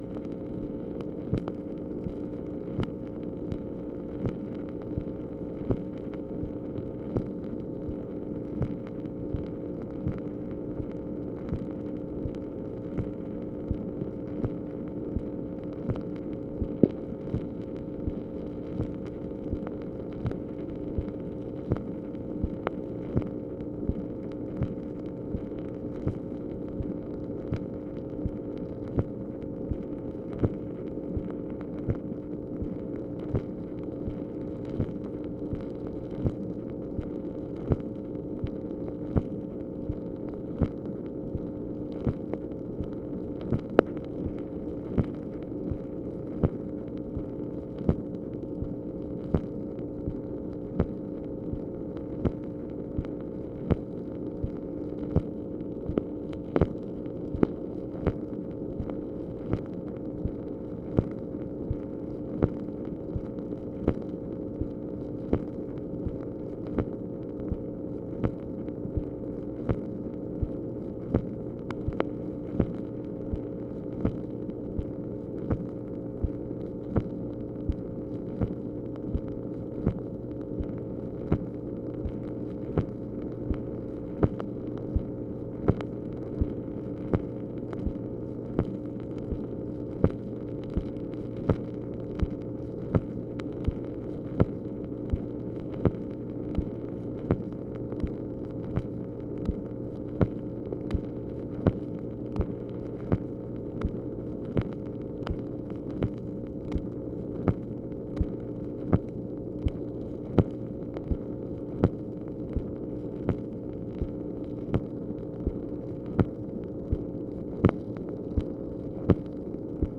MACHINE NOISE, February 14, 1965
Secret White House Tapes